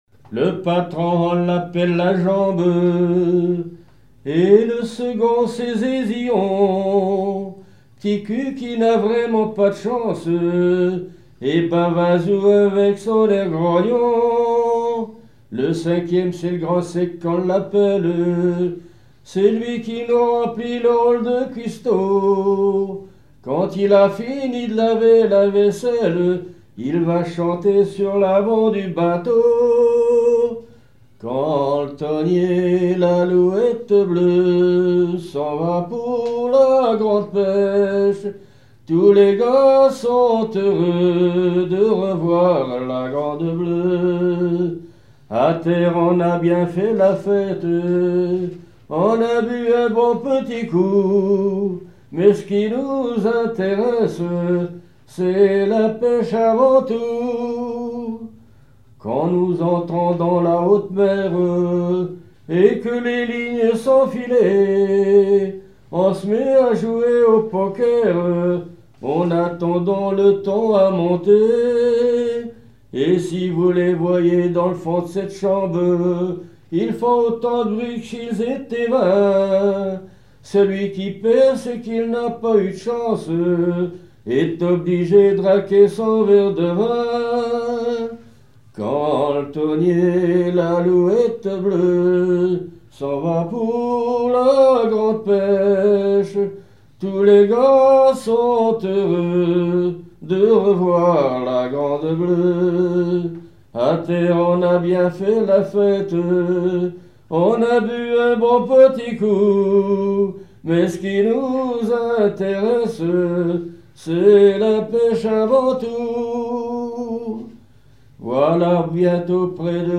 chansons maritimes
Pièce musicale inédite